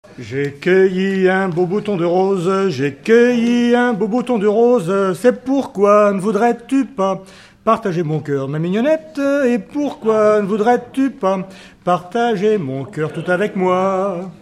Chants brefs - A danser
danse : scottich sept pas
Pièce musicale inédite